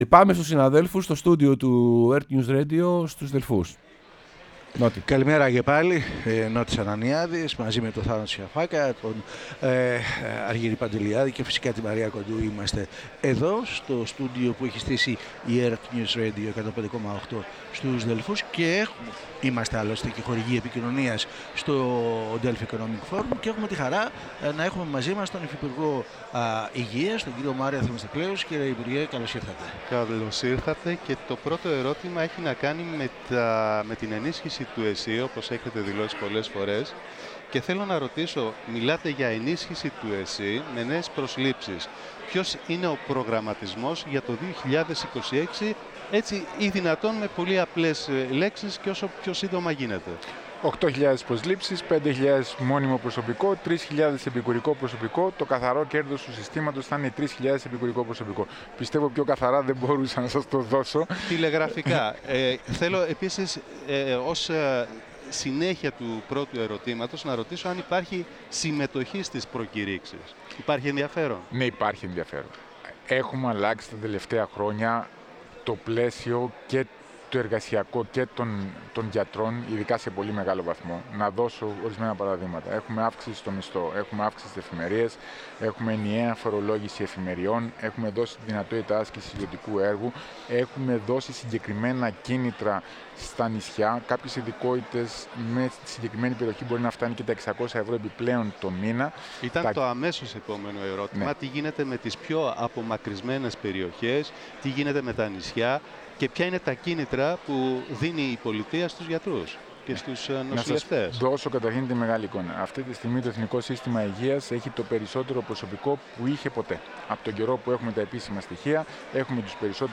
Ο Μάριος Θεμιστοκλέους, Υφυπουργός Υγείας, μίλησε στην εκπομπή «Σεμνά και Ταπεινά»